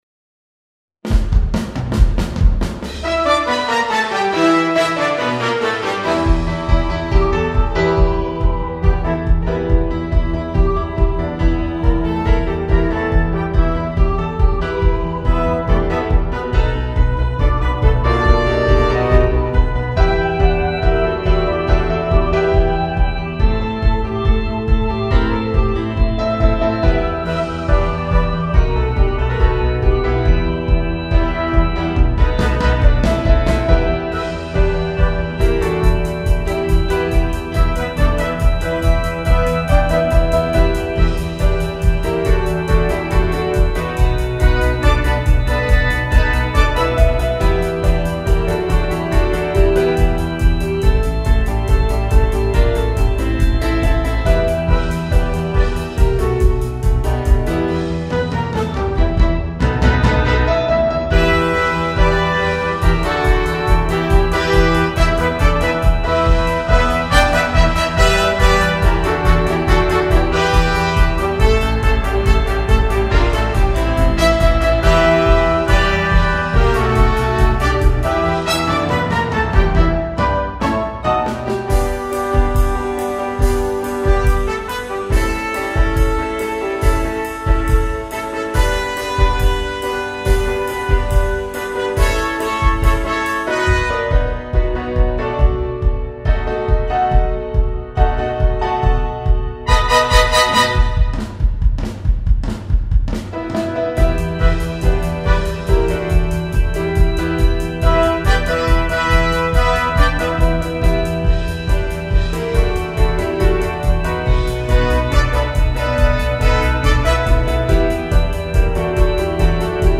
SATB
Opener